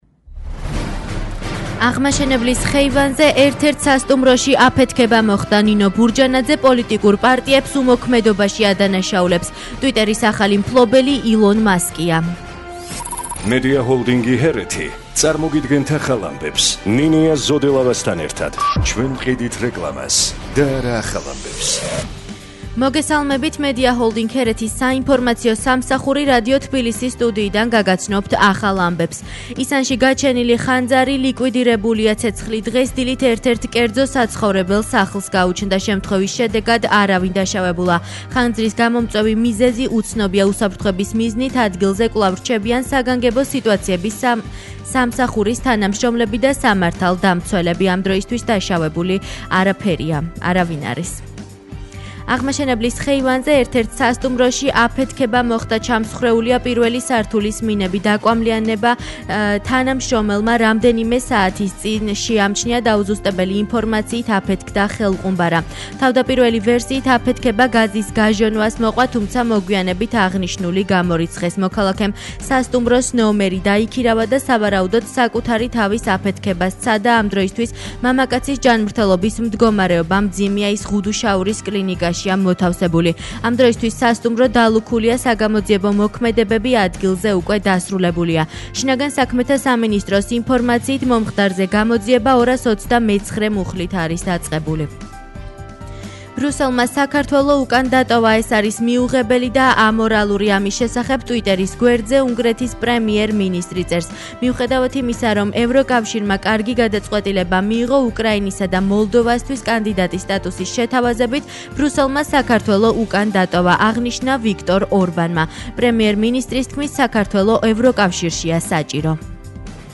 ახალი ამბები 12:00 საათზე